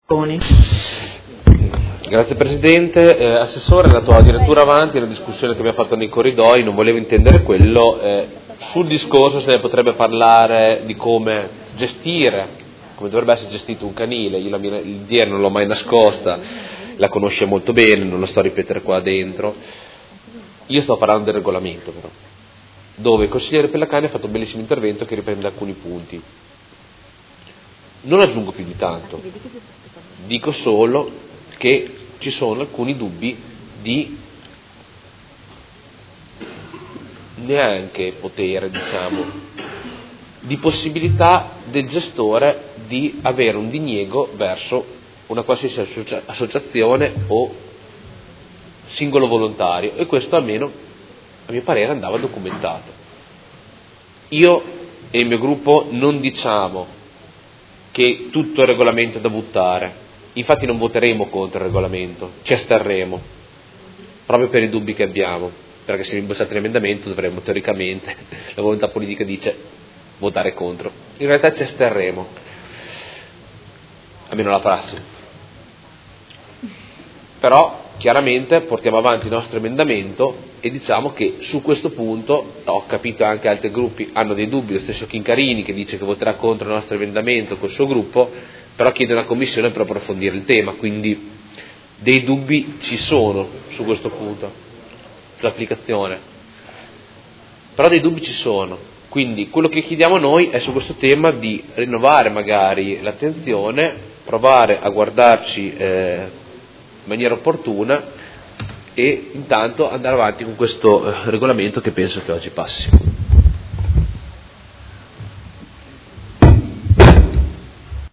Luca Fantoni — Sito Audio Consiglio Comunale
Seduta del 14/12/2017 Dichiarazione di voto.